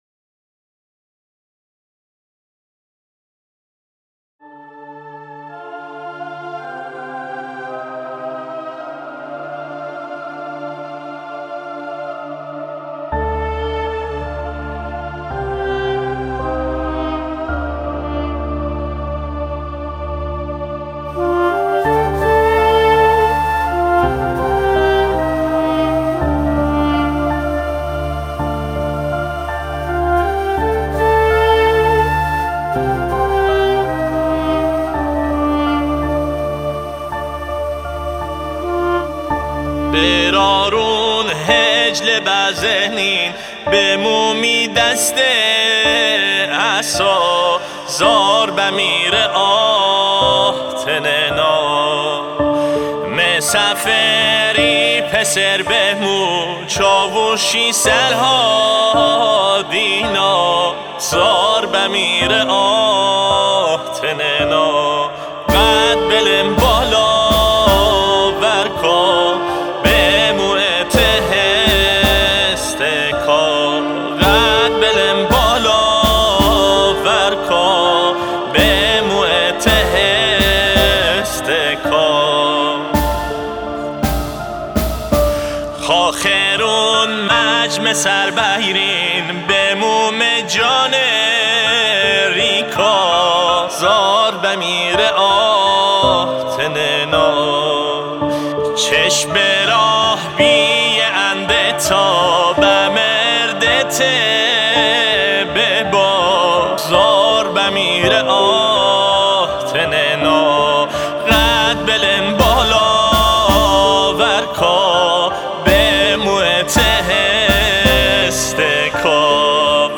خواننده مازندرانی
به زبان و لهجه مازندرانی